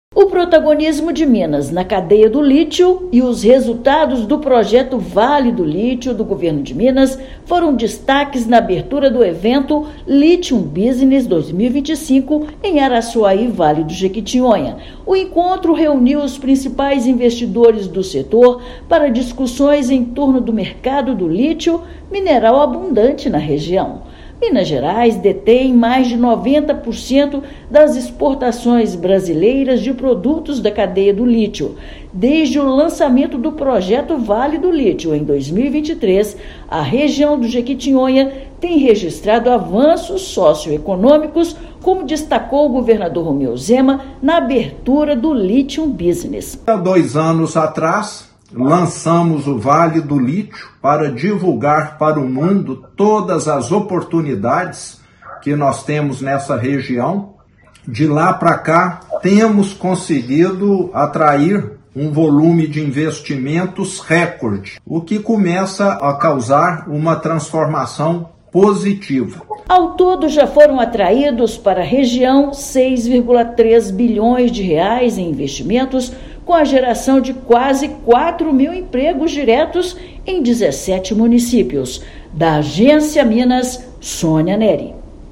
Evento reúne principais investidores do setor e marca os avanços do projeto Vale do Lítio, que já gerou R$ 6,3 bilhões em investimentos e 3,9 mil empregos na região. Ouça matéria de rádio.